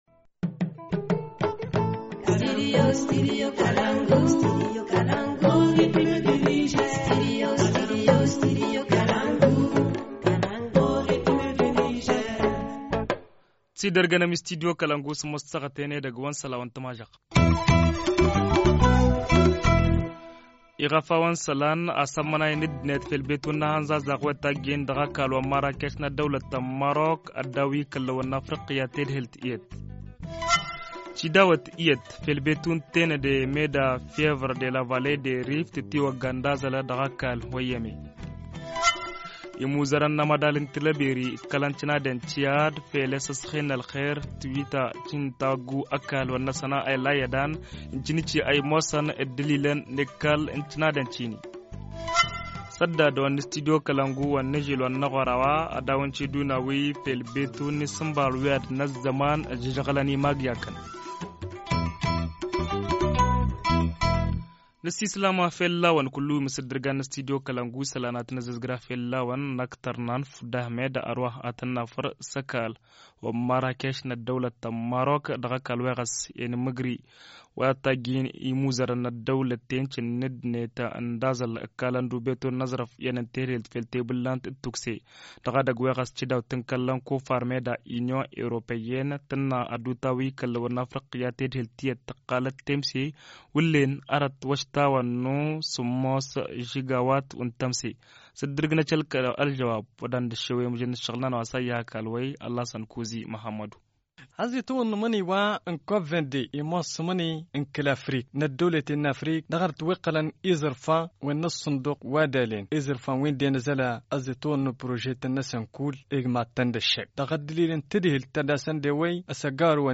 Journal du 17 novembre 2016 - Studio Kalangou - Au rythme du Niger